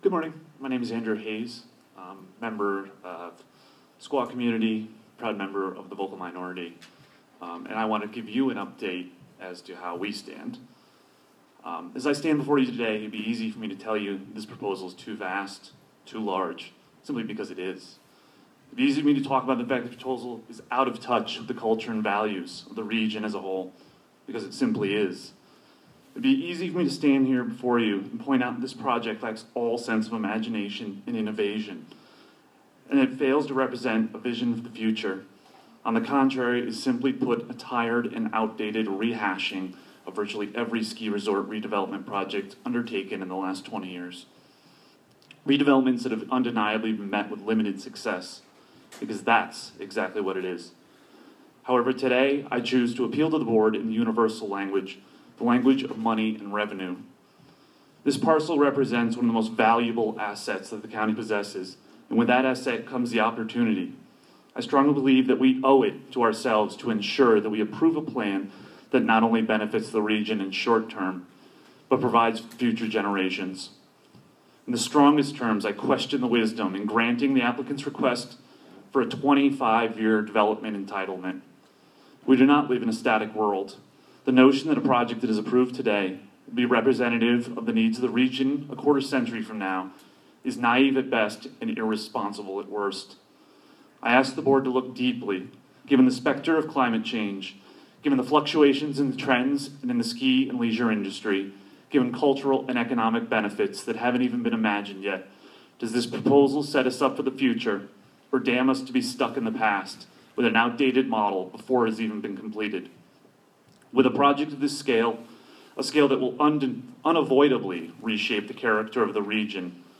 Local Resident Says KSL Developers Are Out of Touch
Resident-Says-KSL-Proposal-Is-Out-of-Touch.mp3